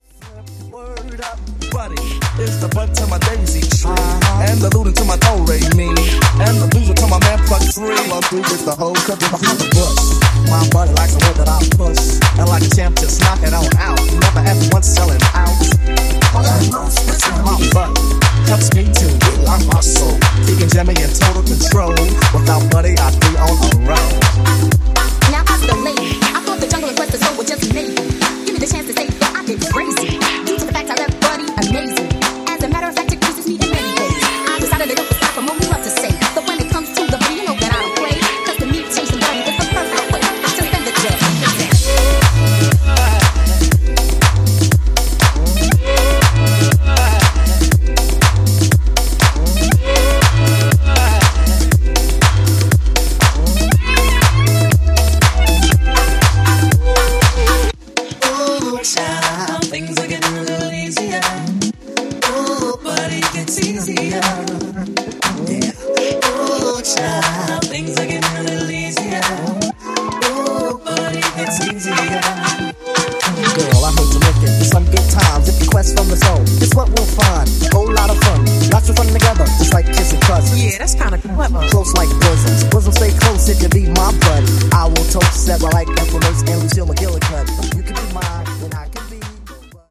a bass heavy Hip House style rework